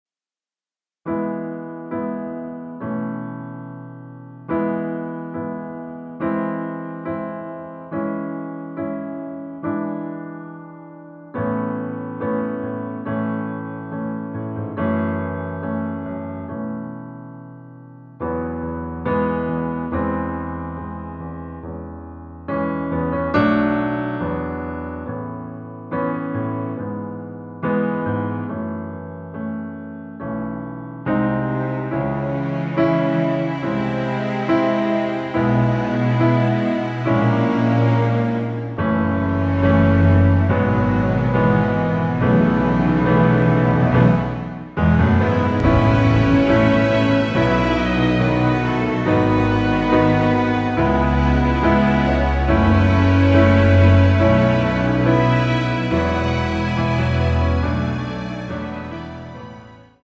전주 없는 곡이라 전주 1마디 만들어 놓았습니다.(미리듣기 참조)
◈ 곡명 옆 (-1)은 반음 내림, (+1)은 반음 올림 입니다.
앞부분30초, 뒷부분30초씩 편집해서 올려 드리고 있습니다.
중간에 음이 끈어지고 다시 나오는 이유는